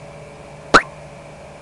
Pop Sound Effect
Download a high-quality pop sound effect.
pop-3.mp3